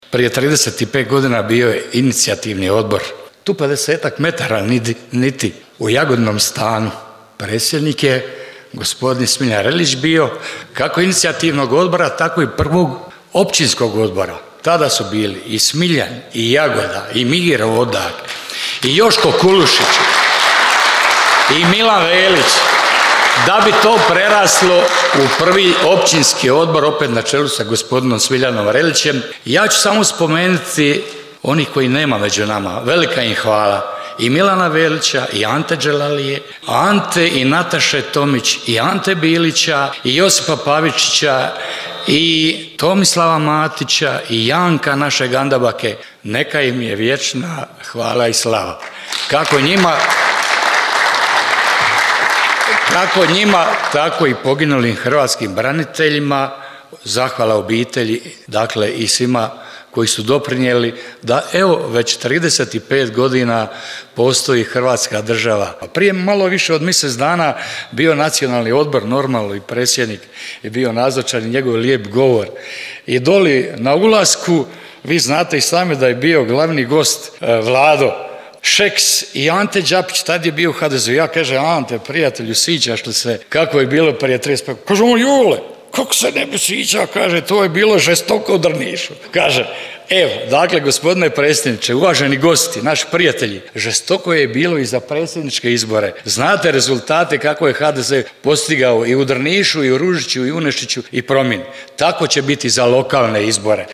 Drniški HDZ proslavio 35. obljetnicu osnutka te održao predizborni skup